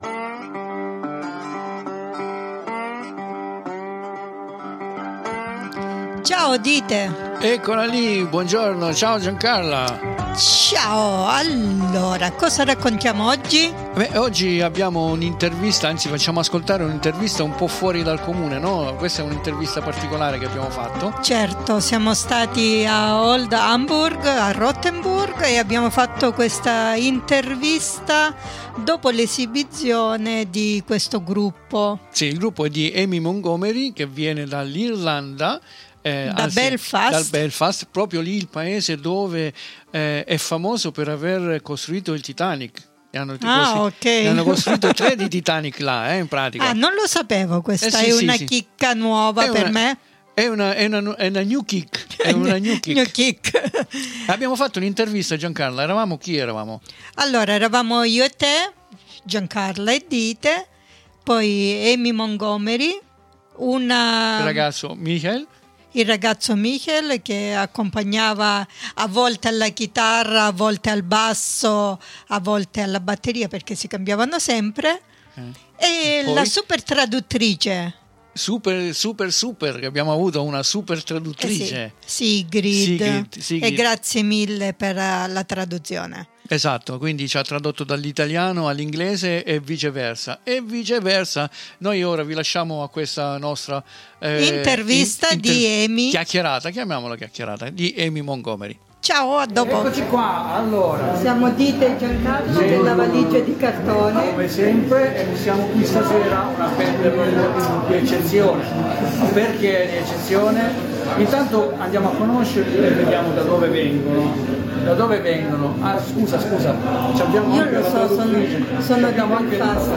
VENERDÍ 22 DICEMBRE 2023 DURANTE IL CONCERTO- SCUSATE I RUMORI DI SOTTOFONDO
ECCOCI QUI IN UNA LOCATION SUGGESTIVA, DAL SAPORE E COLORE MARITTIMO "OLD HAMBURG ROTTENBURG"